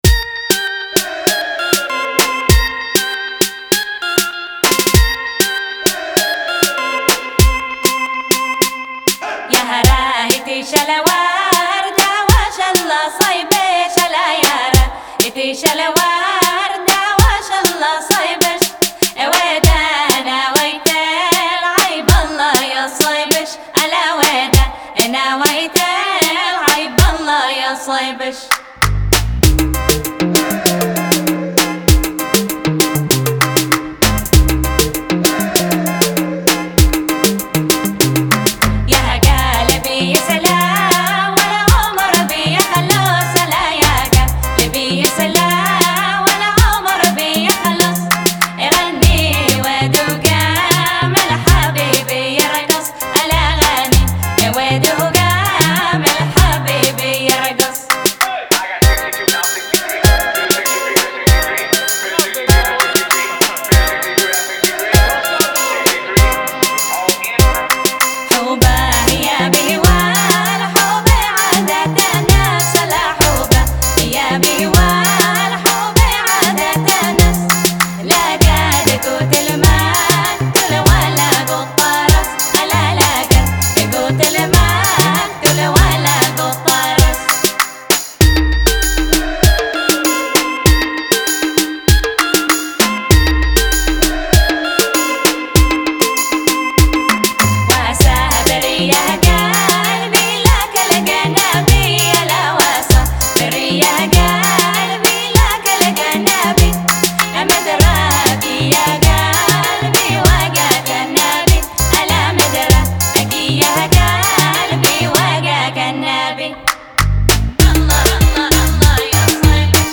Genre: World